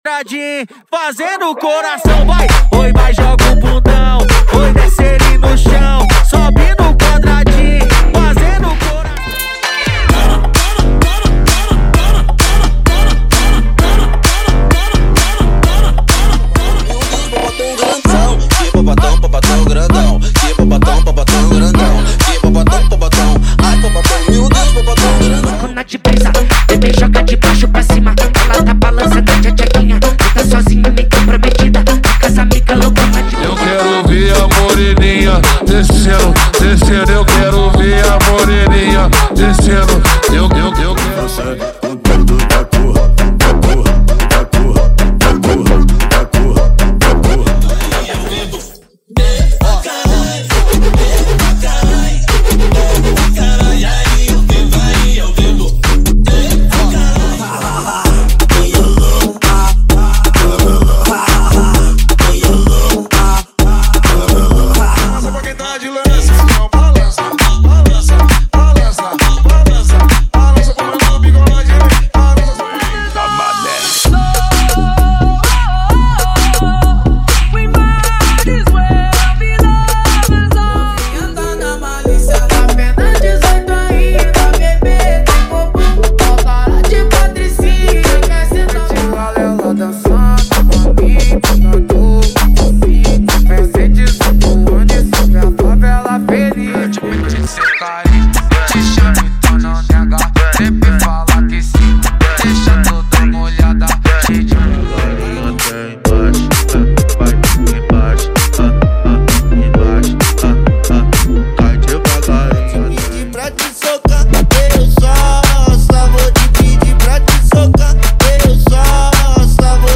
🔥 Eletro Funk Light
Versão Ligth Sem Palavrão
✔ Músicas sem vinhetas